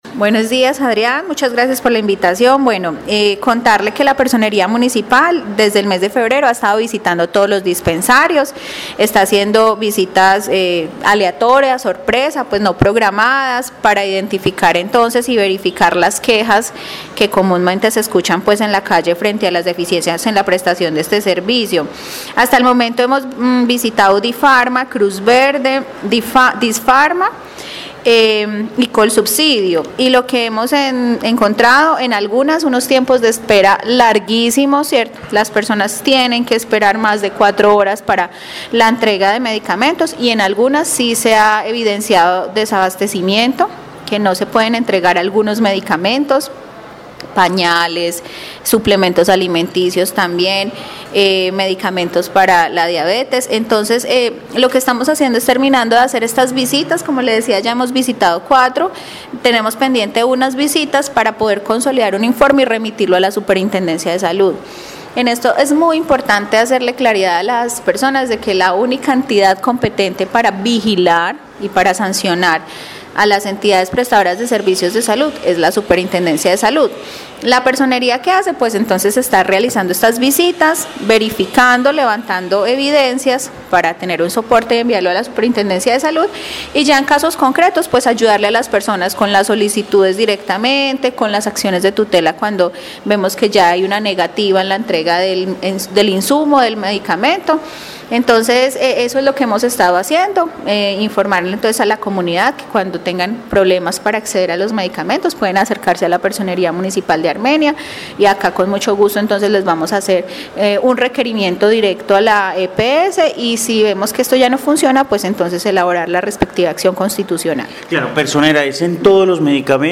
Juliana Victoria Ríos, personera de Armenia